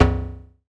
Modular Perc 02.wav